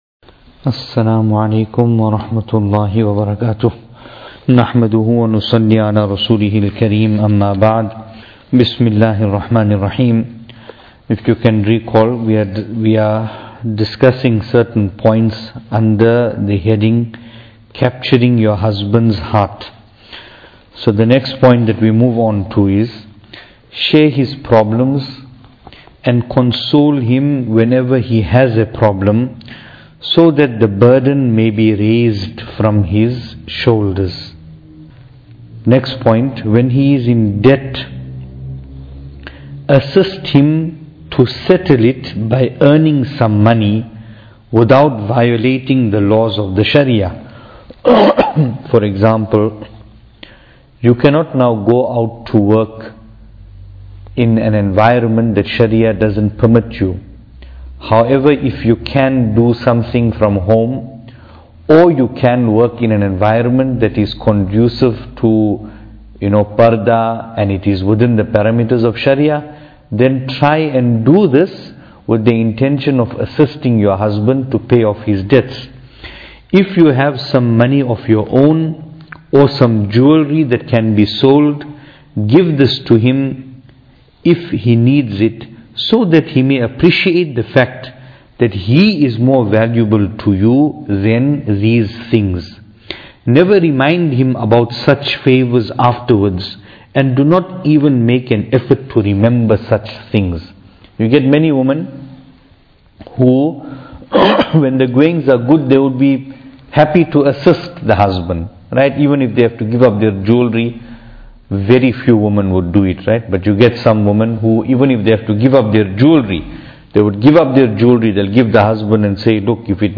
Venue: Pietermaritzburg | Series: Tohfa-e-Dulhan